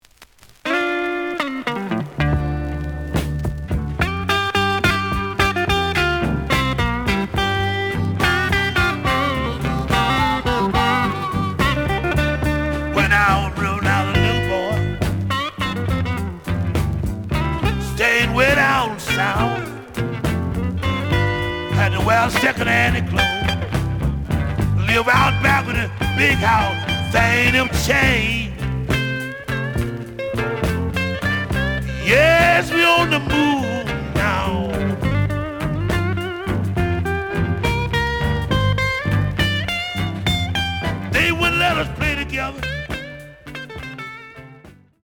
The audio sample is recorded from the actual item.
●Genre: Blues
Slight click noise on both sides due to a cave.